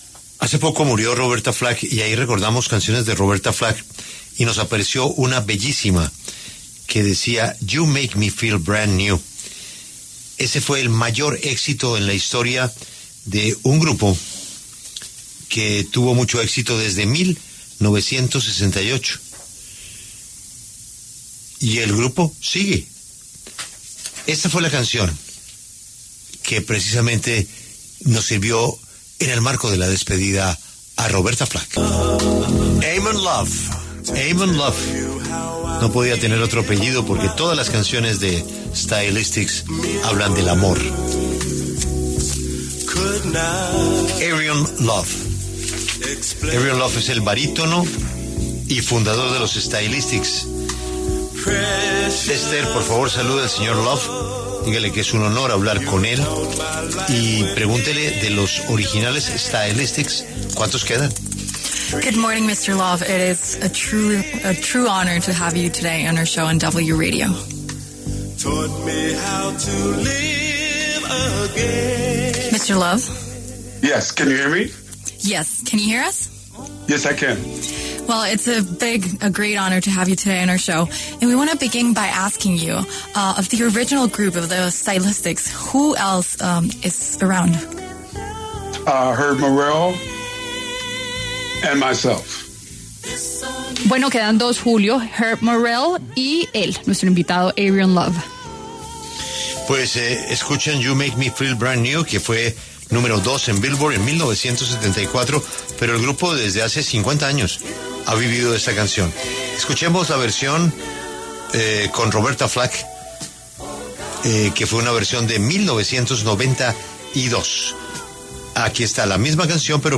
Airrion Love, cantante barítono y miembro fundador de The Stylistics, habló en La W sobre el legado musical de su agrupación.